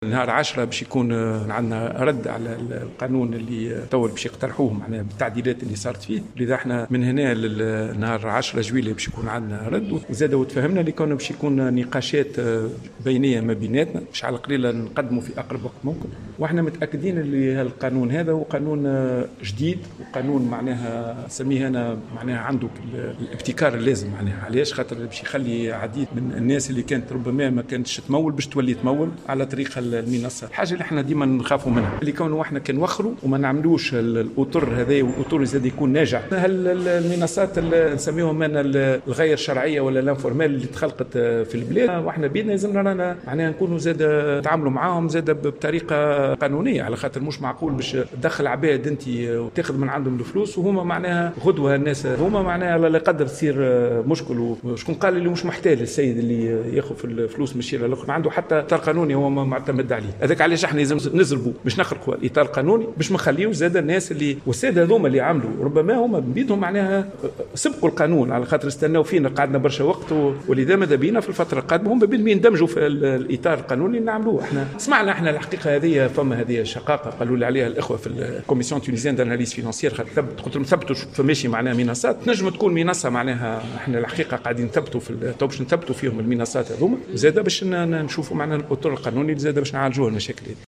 أكد محافظ البنك اامركزي في تصريح لـ"الجوهرة أف أم" اليوم الأربعاء اثر جلسة استماع في لجنة المالية والتّخطيط والتنمية وجود منصات غير شرعية على الانترنت يشتبه في قيامها بعمليات تحيلعلى المواطنين على غرار منصة "شقّاقة".